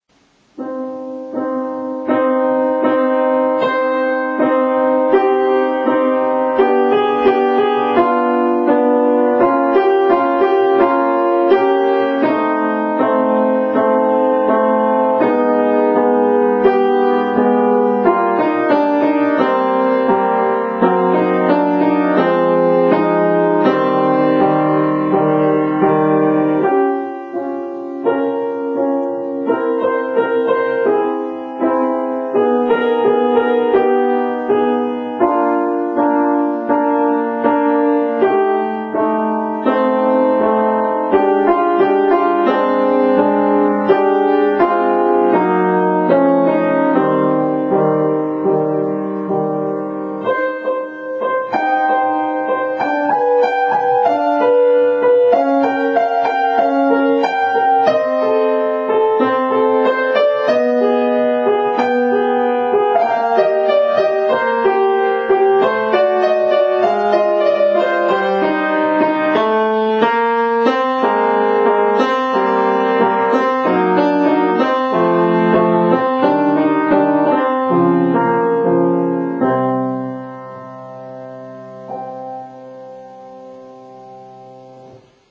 とても民族色の強い作品になっています。